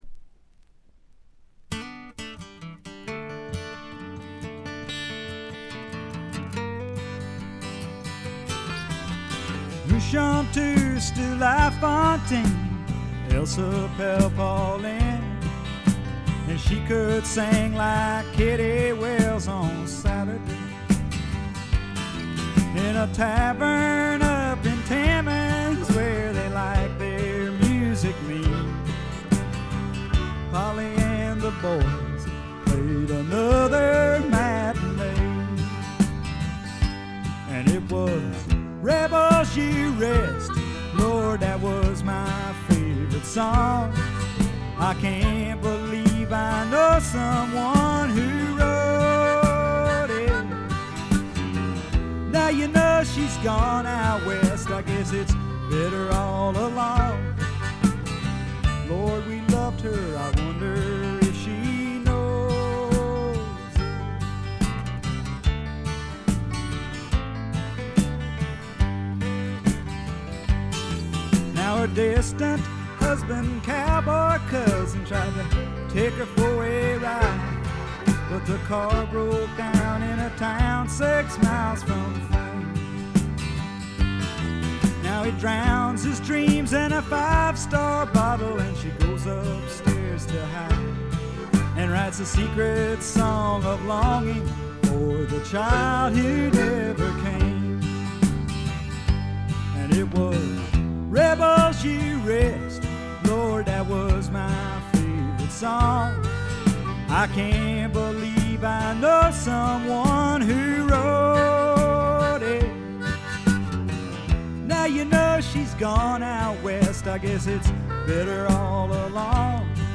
微細なバックグラウンドノイズがわずかに聴かれる程度。
いかにもカナダらしい清澄な空気感と薄味のルーツ系の味付けも心地よいですね。
試聴曲は現品からの取り込み音源です。
Guitar, Mandolin, Autoharp, Vocals
Harmonica, Trumpet, Euphonium
Drums, Congas, Percussion